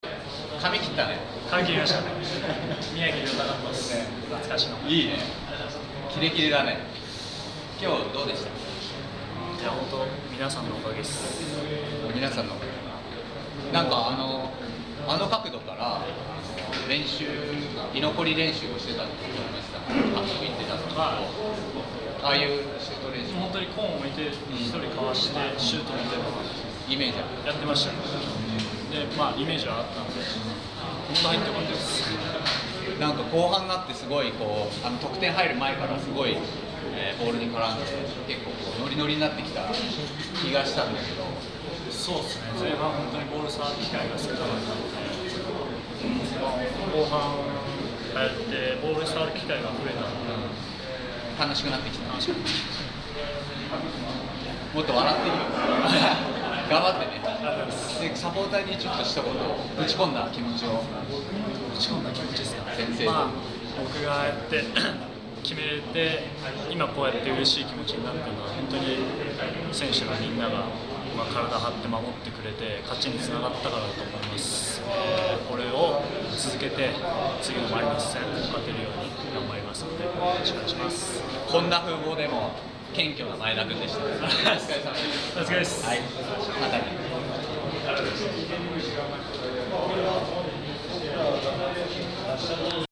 2015 J1 1st 12節 ヴィッセル神戸戦 前田 直輝インタビュー